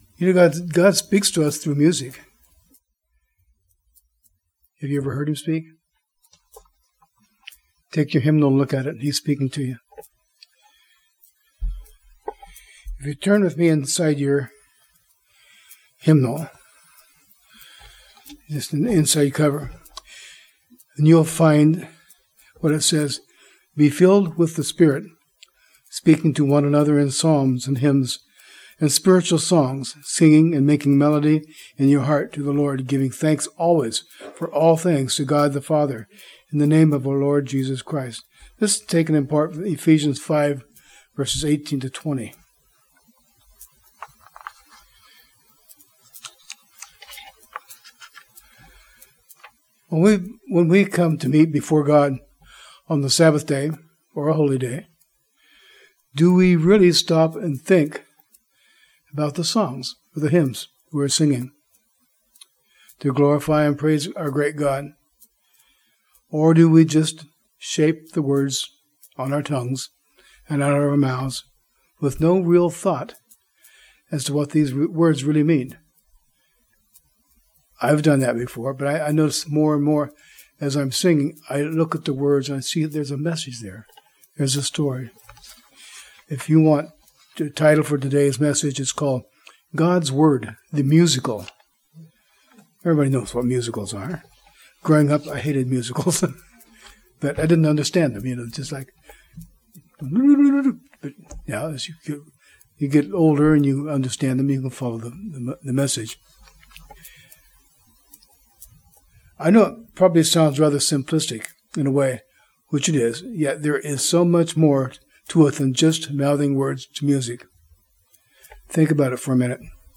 A Musical
Given in Northwest Arkansas